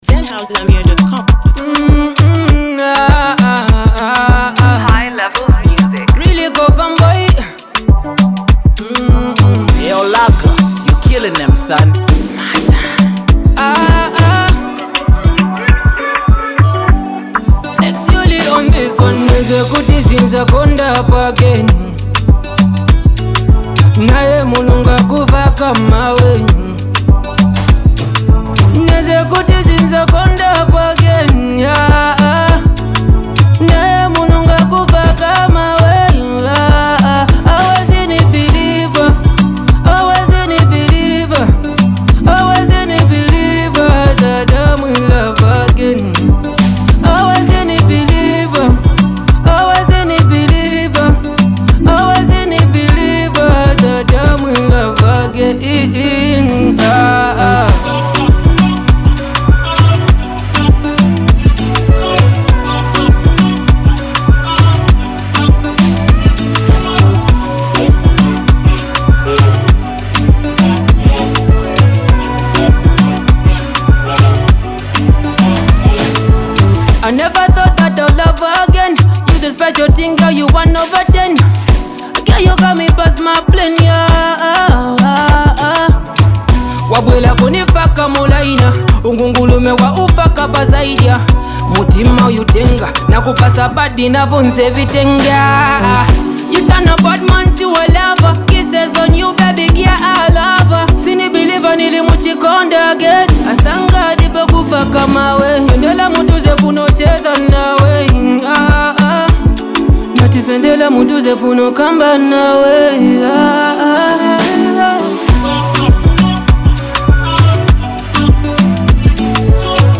soulful masterpiece